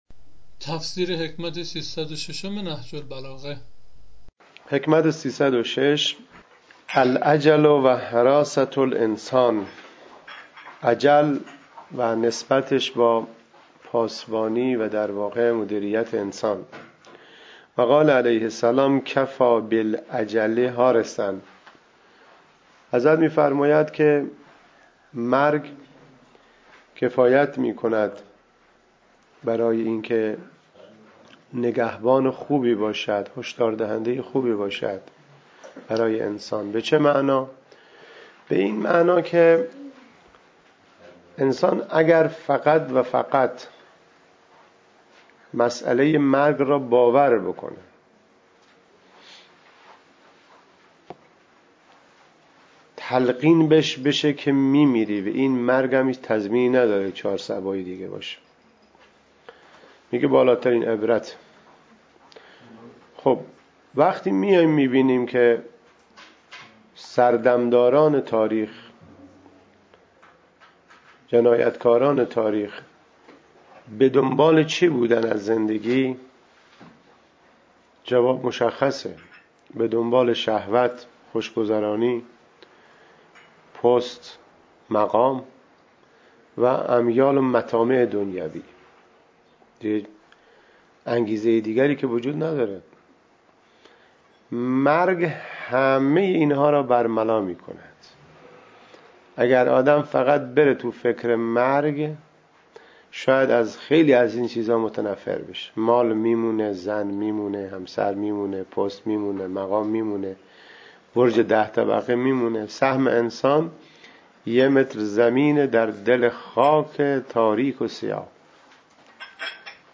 تفسیر صوتی حکمت 306 نهج البلاغه
تفسیر-صوتی-حکمت-306-نهج-البلاغه-C.mp3